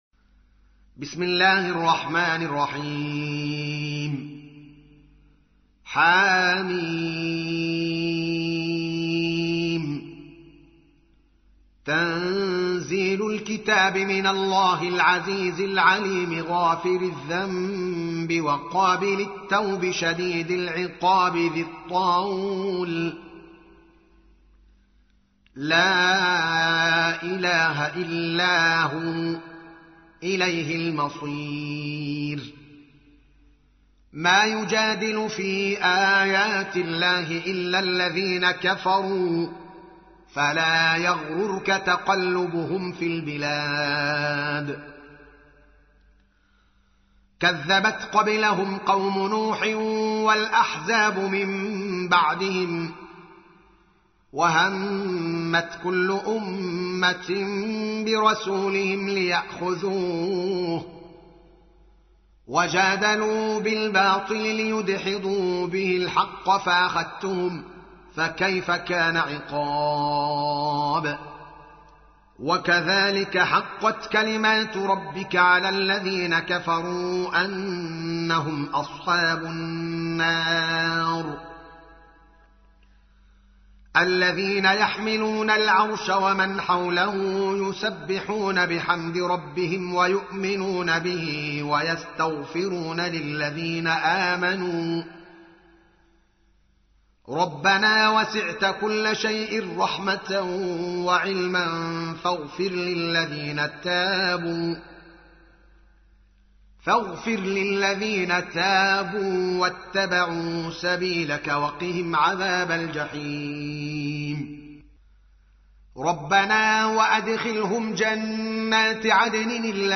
40. سورة غافر / القارئ